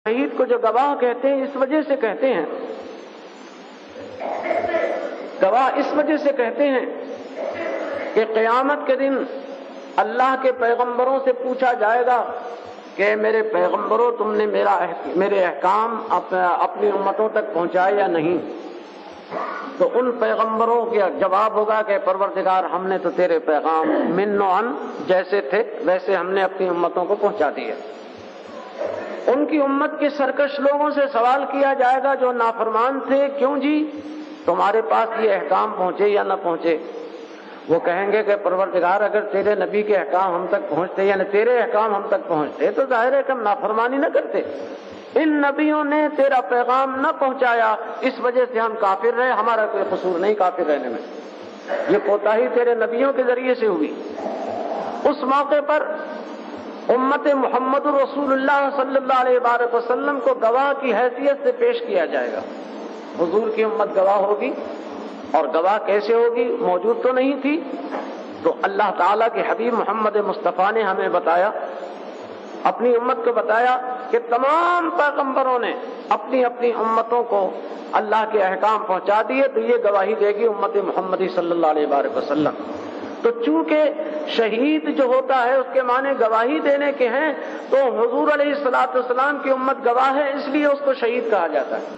Category : Speech | Language : Urdu